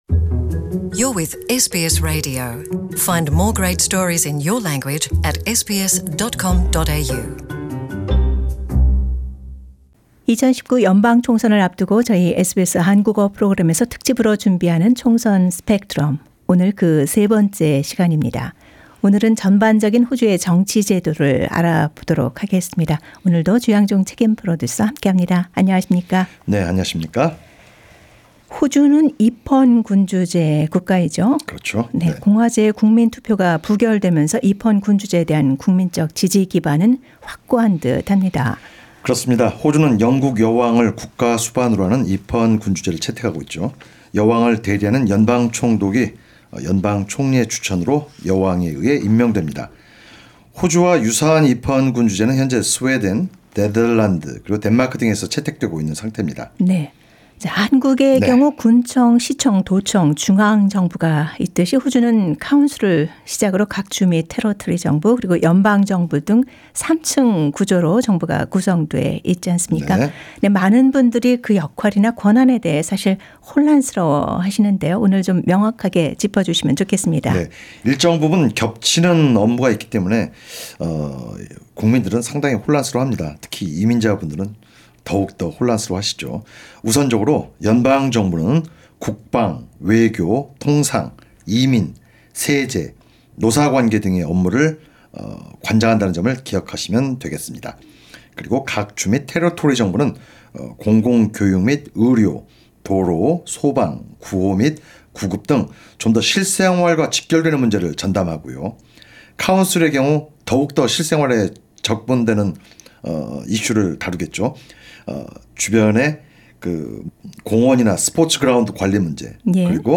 2019 연방총선을 맞아 SBS 한국어 프로그램에서는 연방총선 관련 내용을 알기 쉽게 설명하는 '총선 스펙트럼' 코너를 진행합니다.